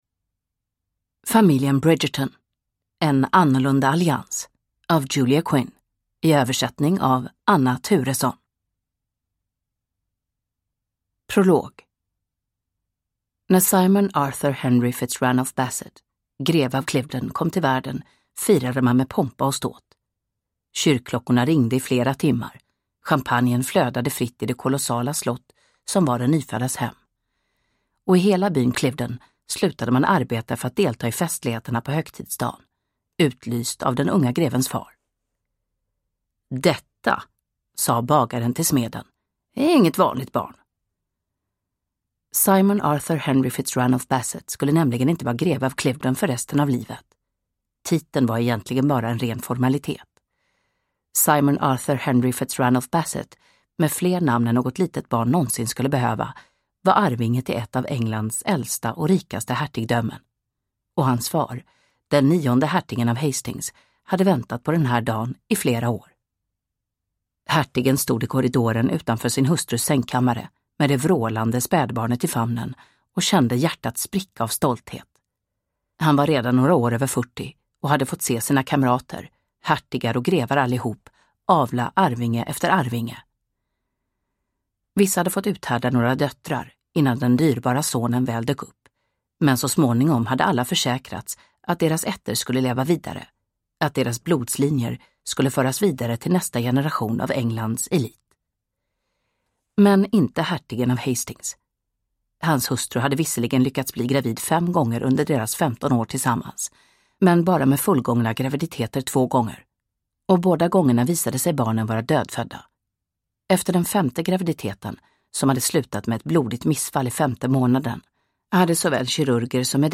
En annorlunda allians – Ljudbok – Laddas ner